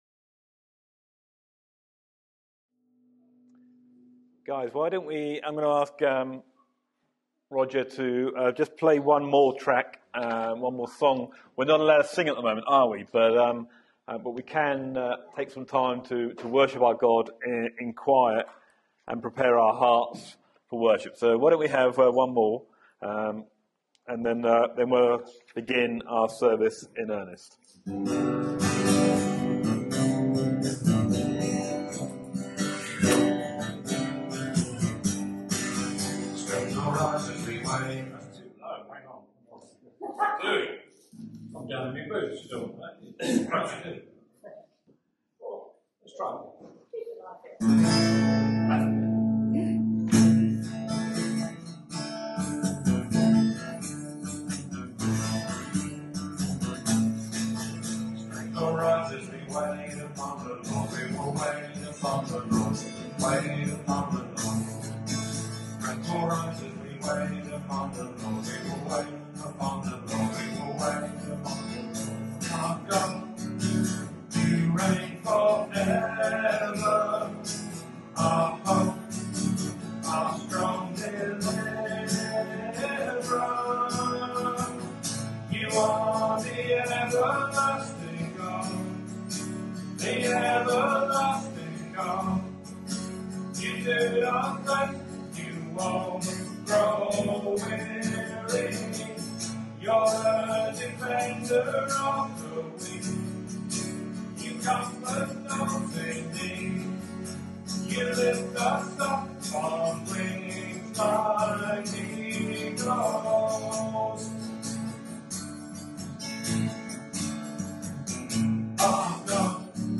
Sunday Worship 2nd May 2021 – Matthew: Beware Tradition